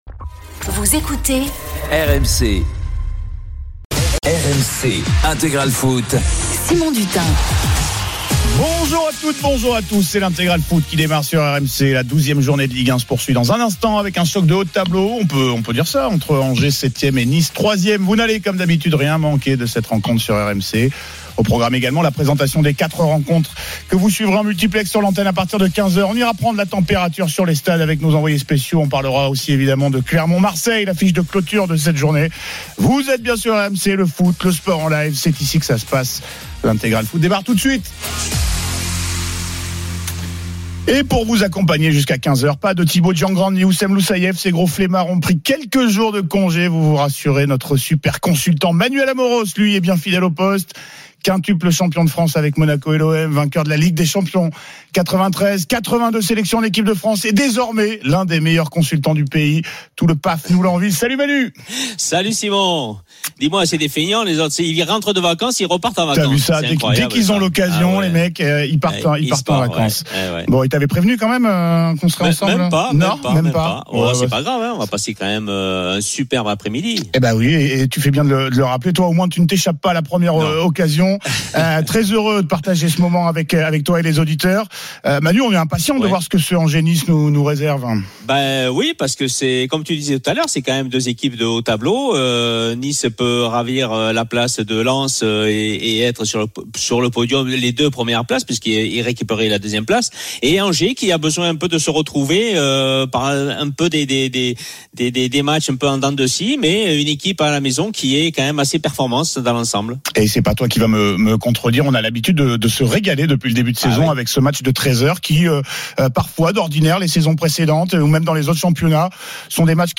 Tous les matchs en intégralité, sur RMC la radio du Sport.